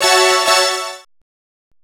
Synth Lick 50-06.wav